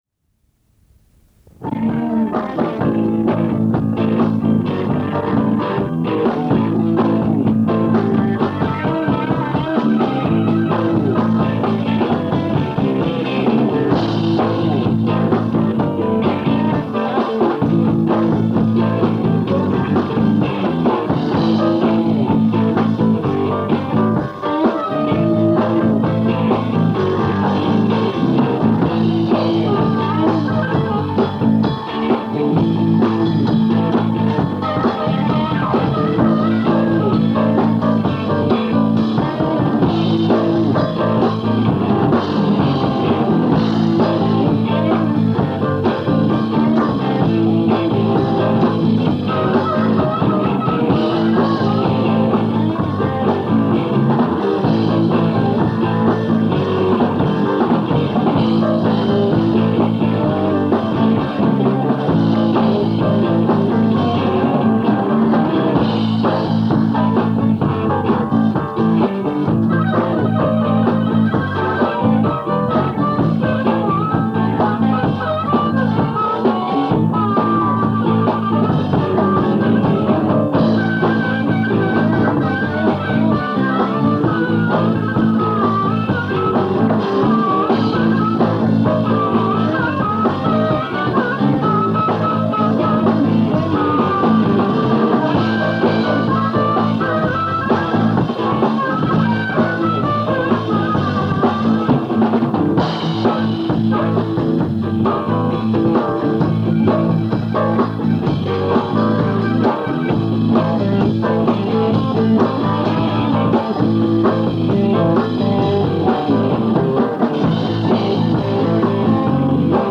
Impro
guitare
piano
harmonica
basse
batterie